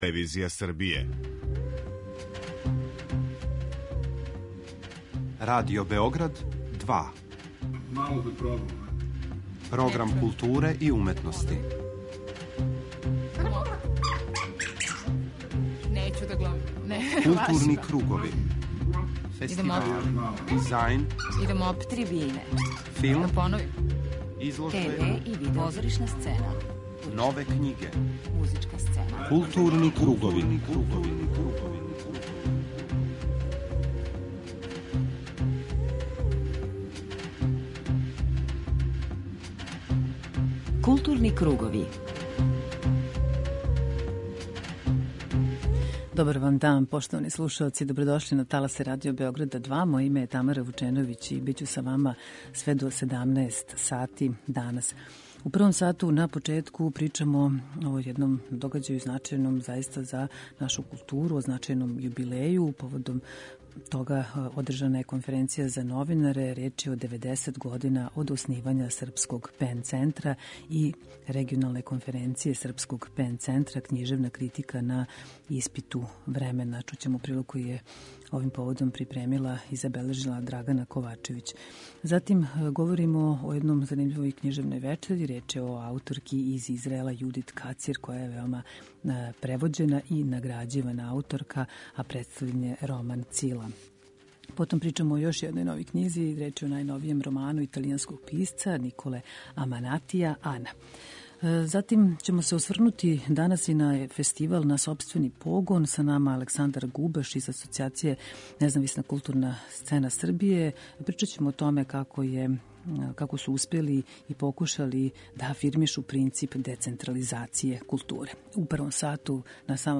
Једна од тема у Златном пресеку је Фестивал Екстравагантна тела: Злочин и казна, који је одржан 20. и 21. новембра у Дому омладине Београда.